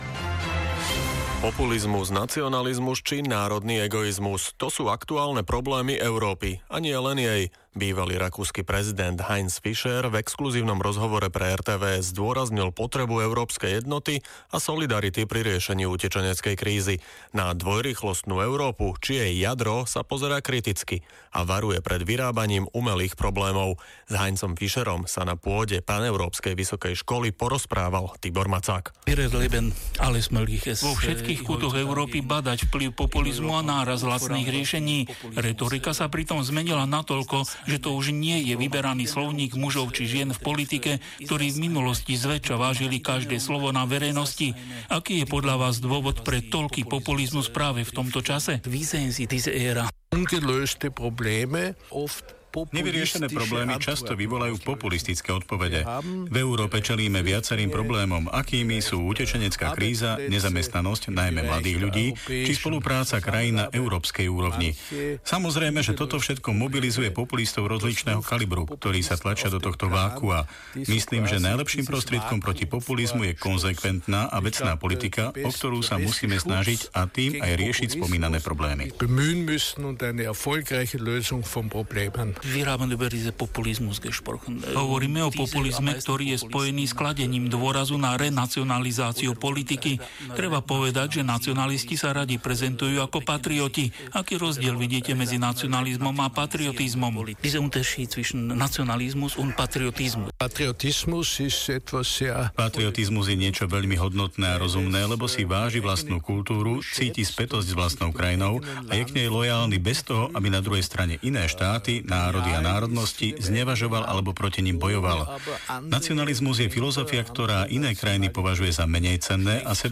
Vypočujte si rozhovor s Dr. Fischerom z vysielania RTVS.
heinz_fischer_rozhovor_radio_slovensko.mp3